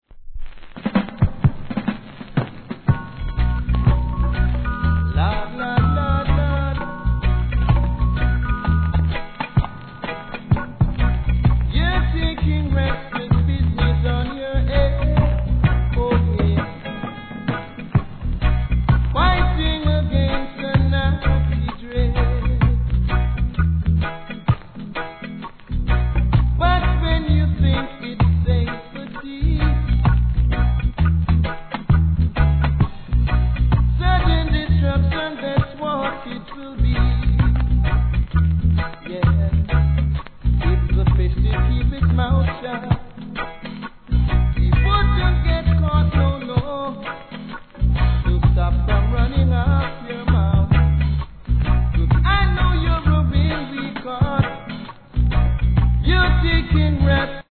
傷のた序盤にノイズ目立ちます
REGGAE